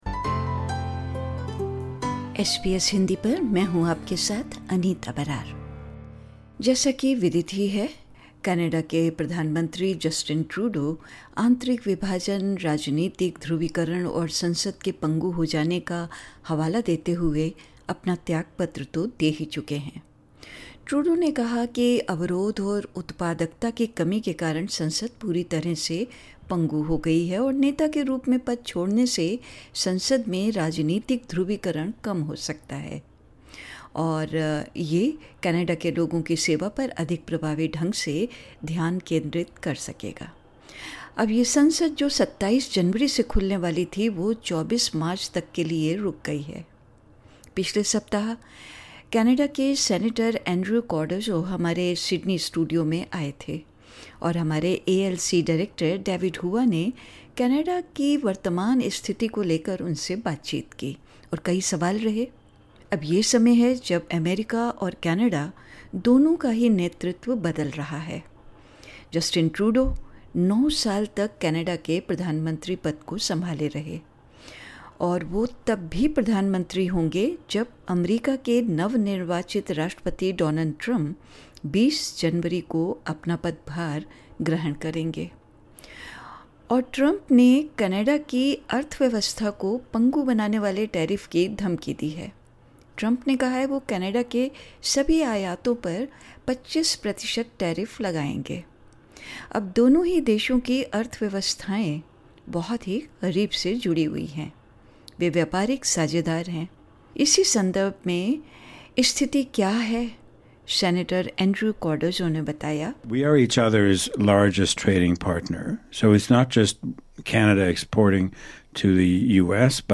Canadian Prime Minister Justin Trudeau announced his resignation, attributing it to internal divisions, political polarisation, and a stagnant Parliament. During a recent visit to SBS studio, Canadian Senator Andrew Cardozo addressed pressing issues, including Trump’s threats to impose tariffs, concerns about the future of Canadian identity, and the prospects for Canada's multiracial society. He shared his insights in a conversation